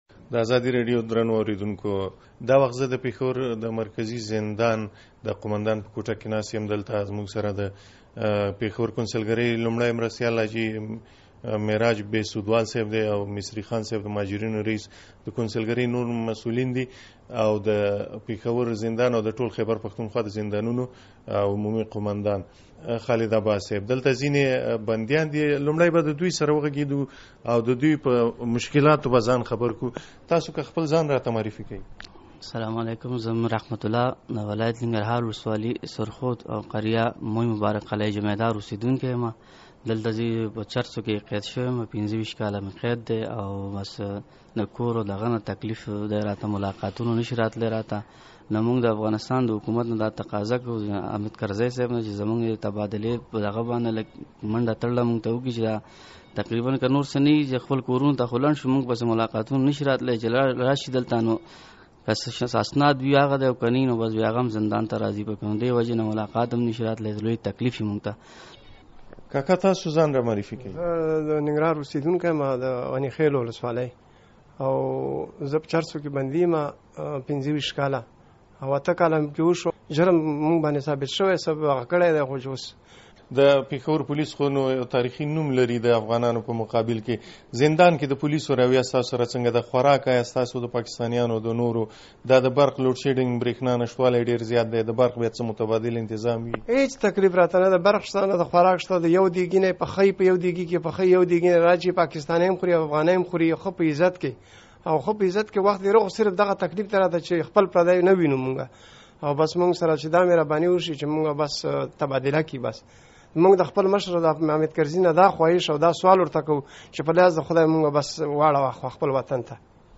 مرکه په پیښور کې له افغان بندیانو سره